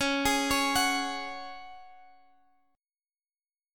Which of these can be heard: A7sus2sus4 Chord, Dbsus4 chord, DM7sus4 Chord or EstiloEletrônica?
Dbsus4 chord